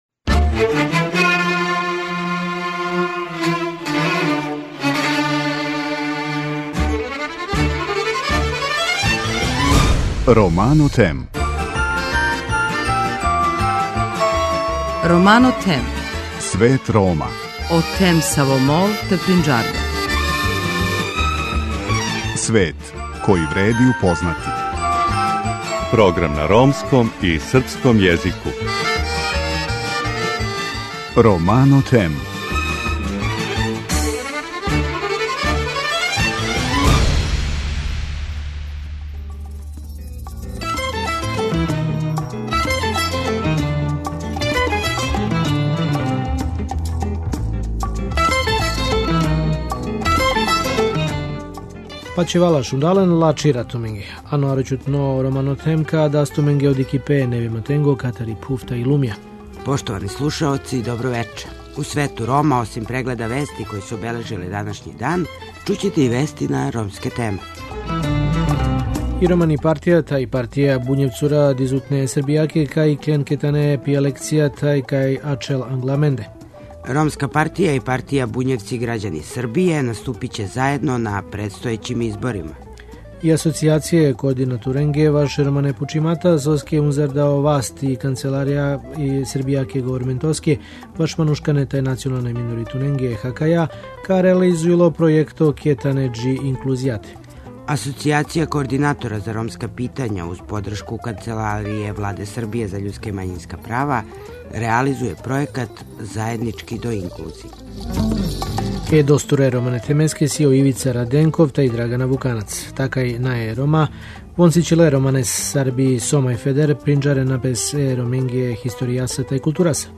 У емисији ћете чути и извештај са конференције Рани, присилни и децији бракови у Србији.